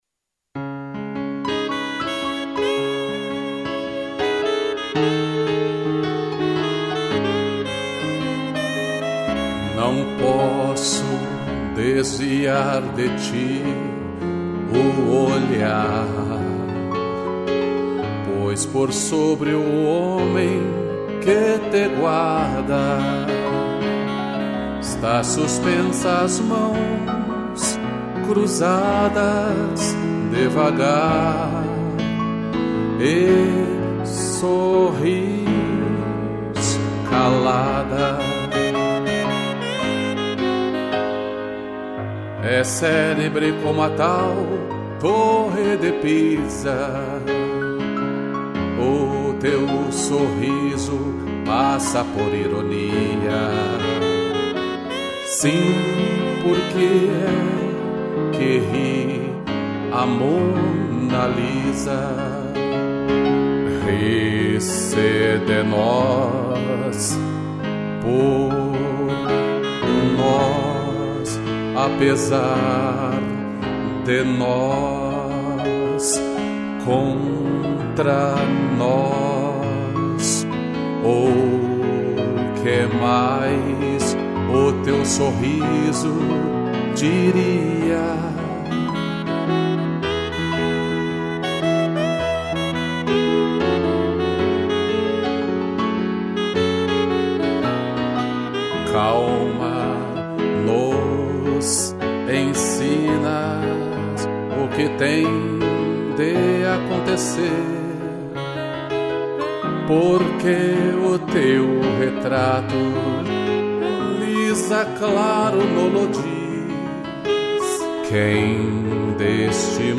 voz
2 pianos e sax